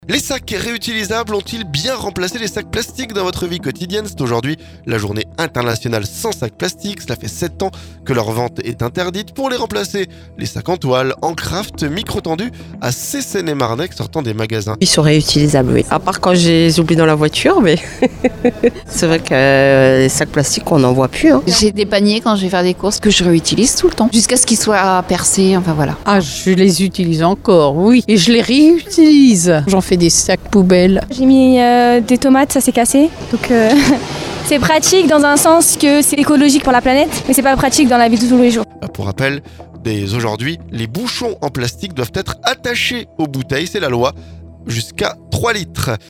Micro tendu à ces Seine-et-marnais sortant des magasins.